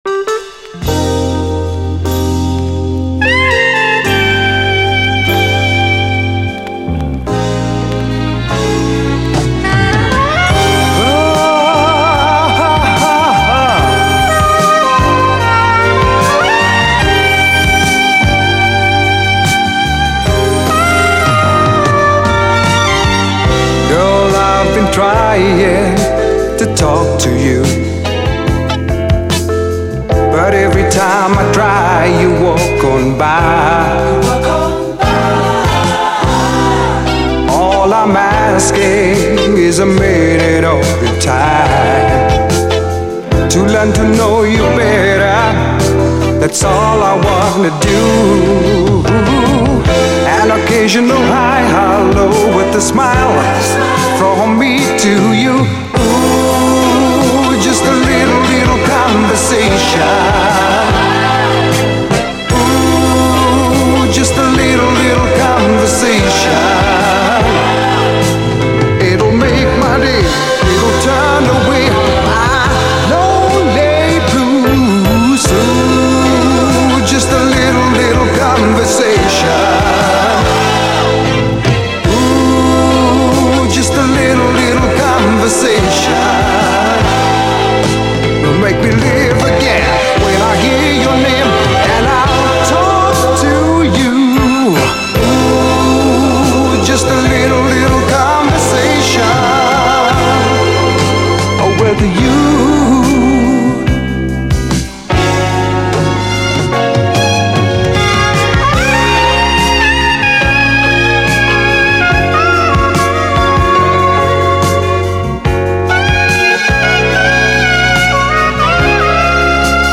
SOUL, 70's～ SOUL, 7INCH
南アフリカ時代の最高哀愁メロウ・モダン・ソウル！AOR的でもある甘く流麗なメロウネス！